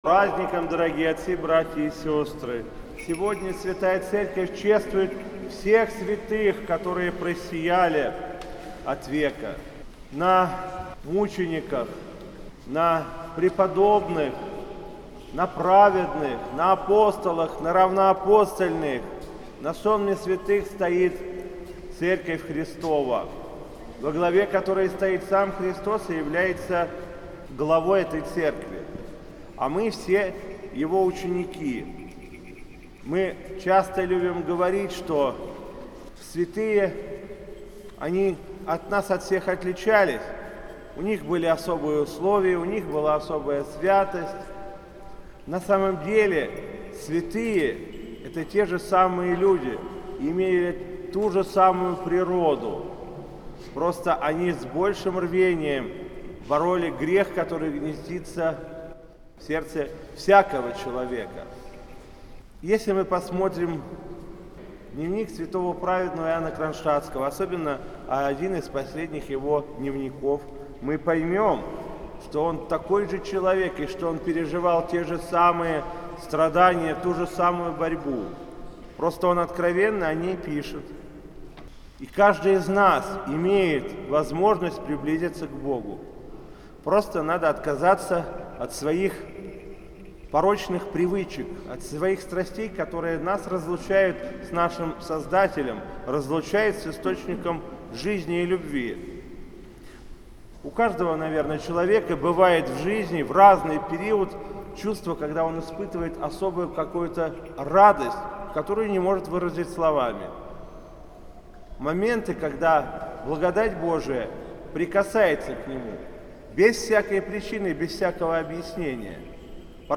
С своем архипастырском слове по окончании Литургии митрополит Вологодский и Кирилловский Игнатий, обращаясь к присутствующим, говорил о том, что святые - это такие же люди, как мы, что святым может стать каждый из нас, нужны не какие-то особенные таланты, а решимость послужить Христу, построить свою жизнь по заповедям Спасителя.